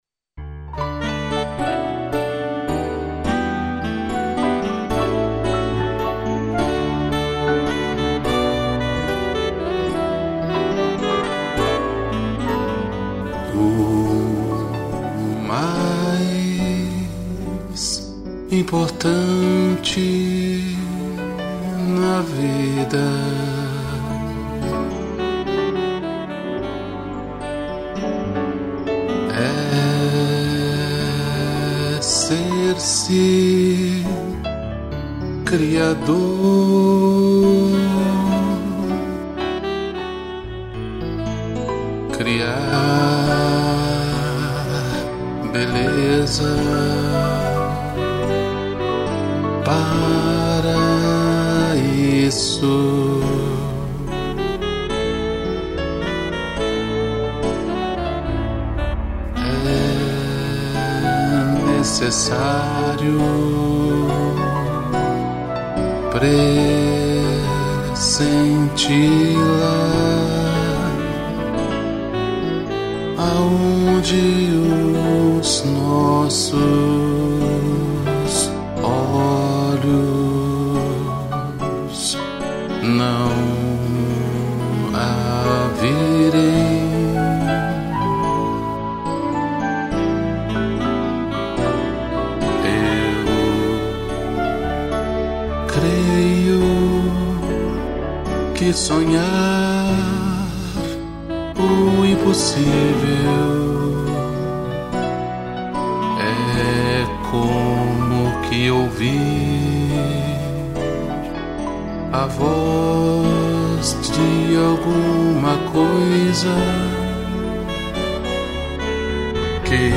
voz
2 pianos, sax, flauta e cello